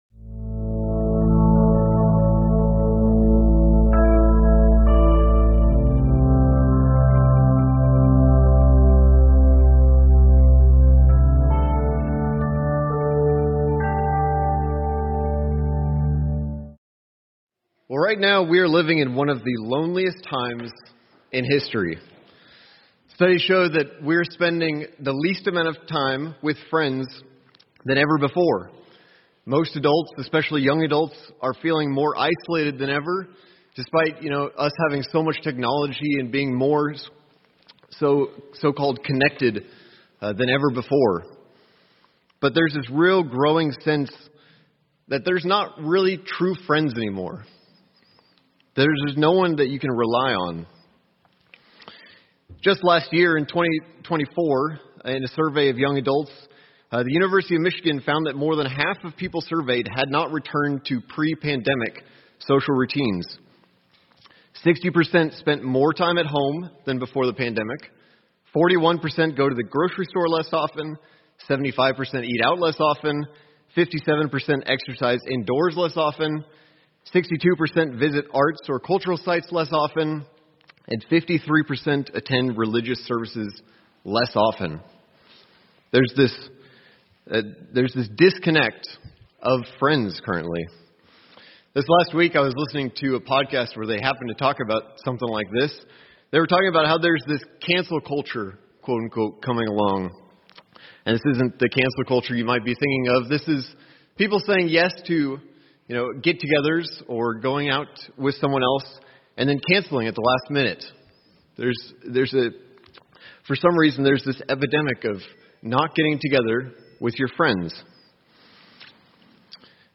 Sermons
Given in Tulsa, OK Oklahoma City, OK